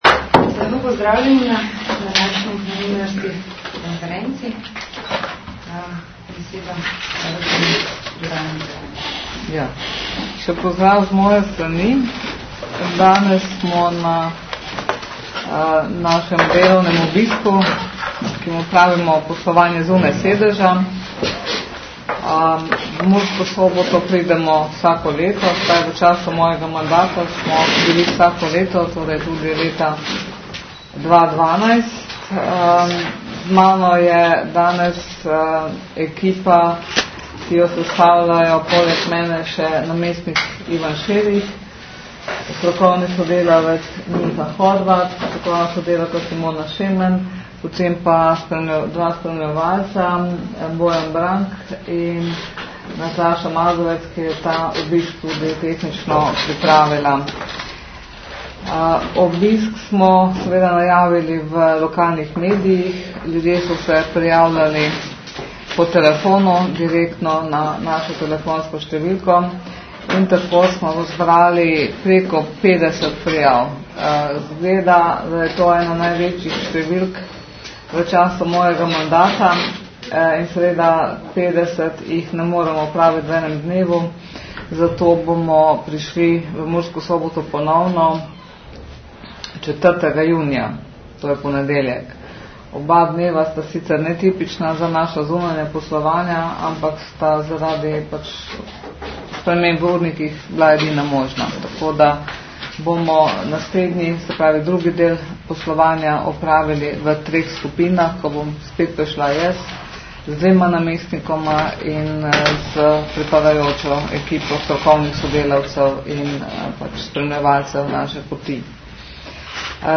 19.07.2012 Varuhinja vložila na ustavno sodišče zahtevo za oceno ustavnosti 143. člena ZUJF ZVOČNI POSNETEK novinarske konference, na kateri je varuhinja z namestnikoma Tonetom Dolčičem in Jernejem Rovškom predstavila Zahtevo za oceno...